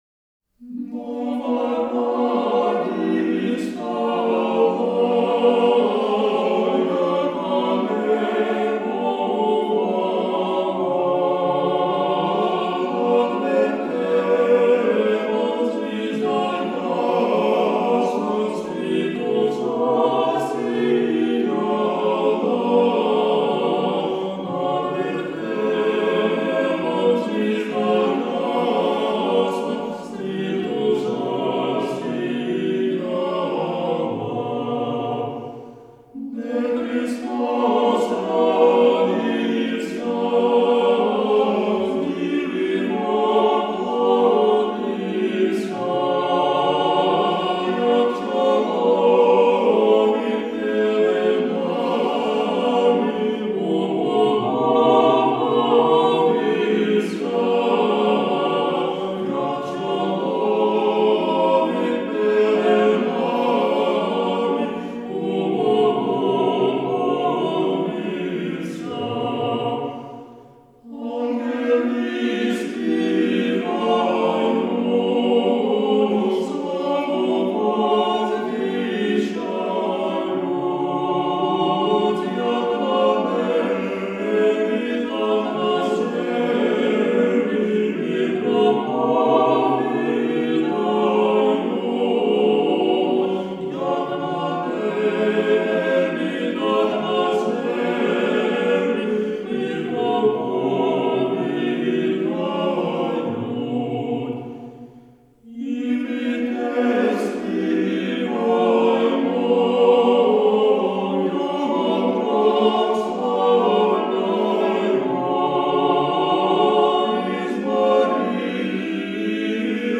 Рождественские колядки
Хор храма Святителя Николая в Заяицком